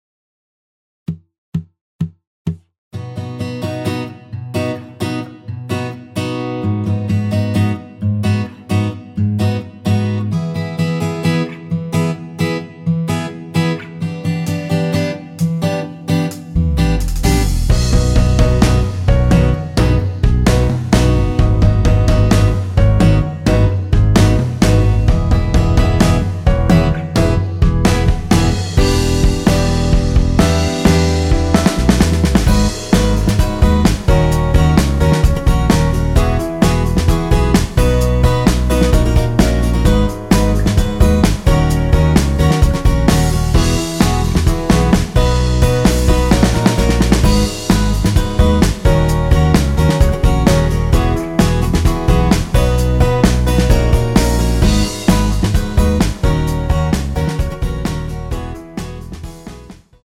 전주 없이 시작하는 곡이라 4박 카운트 넣어 놓았습니다.(미리듣기 확인)
원키에서(+6)올린 MR입니다.
앞부분30초, 뒷부분30초씩 편집해서 올려 드리고 있습니다.